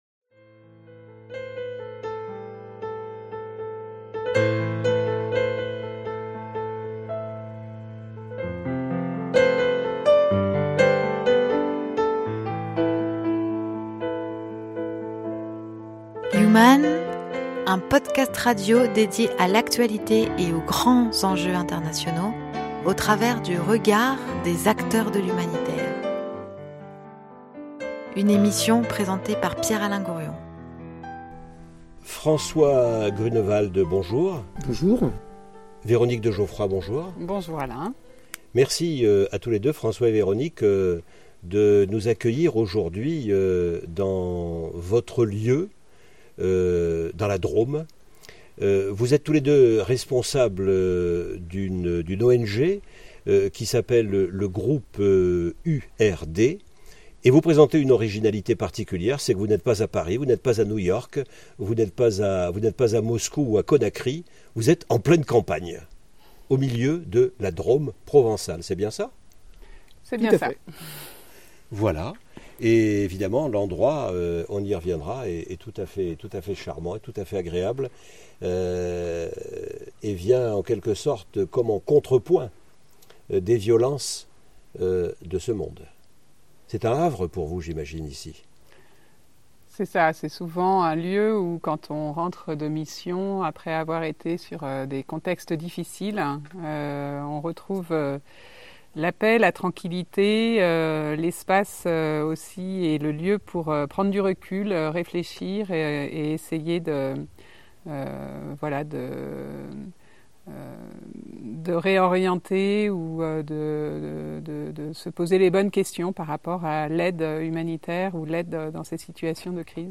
Il y a du vent dans leur jardin ... et dans les voiles qui poussent leur bateau ...